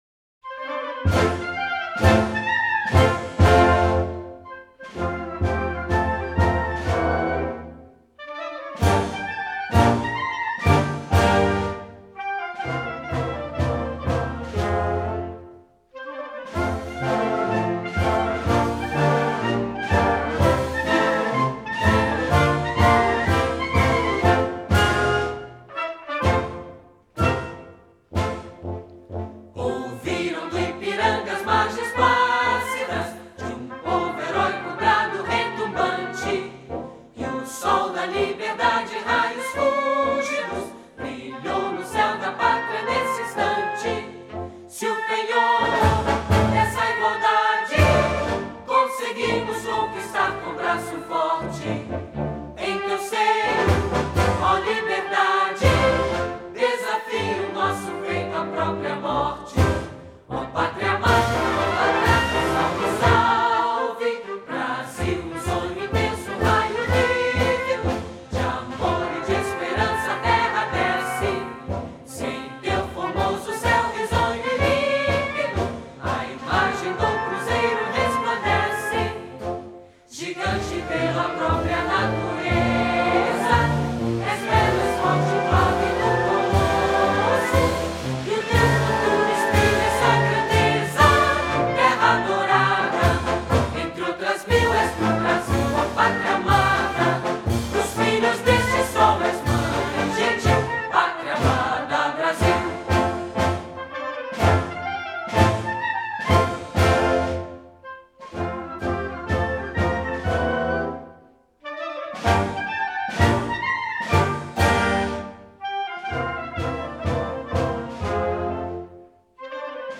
Hino Nacional Brasileiro (cantado).mp3